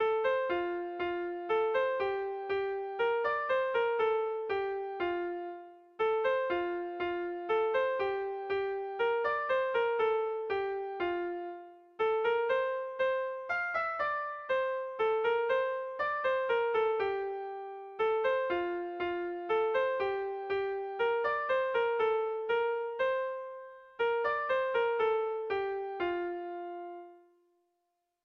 Erlijiozkoa
Zortziko ertaina (hg) / Lau puntuko ertaina (ip)